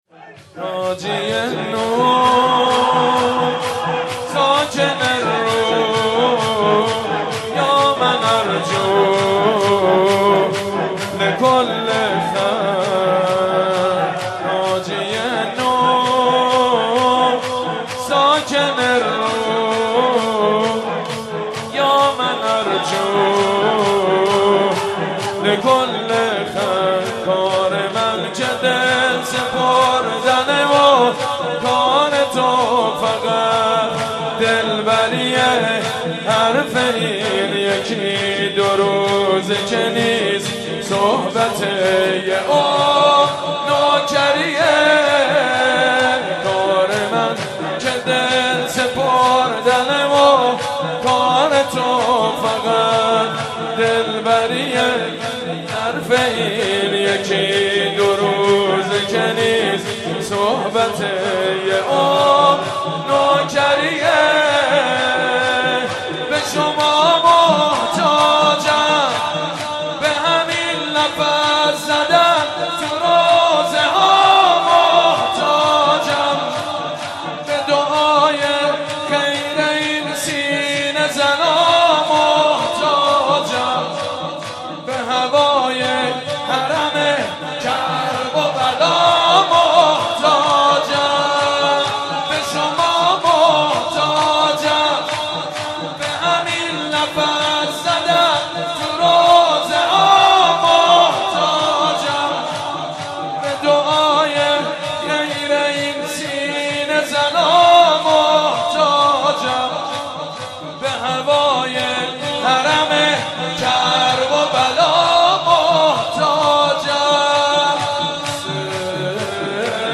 مناسبت : شهادت امام موسی‌کاظم علیه‌السلام
مداح : سیدمجید بنی‌فاطمه قالب : شور